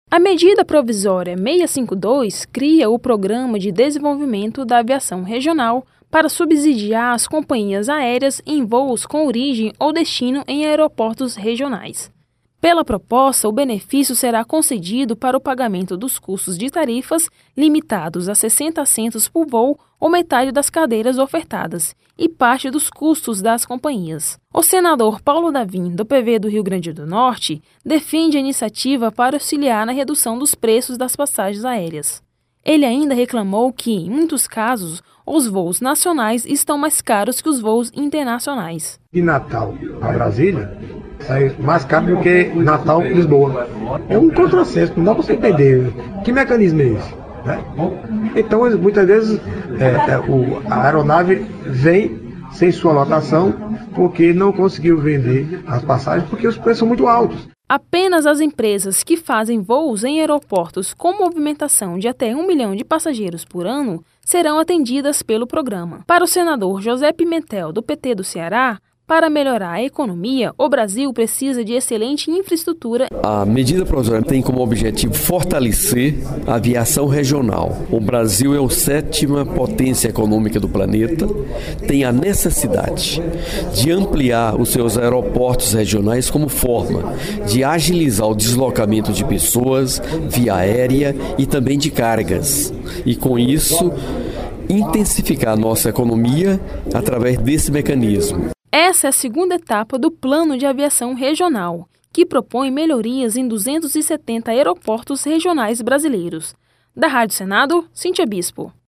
O senador Paulo Davim, do PV do Rio Grande do Norte, defende a iniciativa para auxiliar na redução dos preços das passagens aéreas.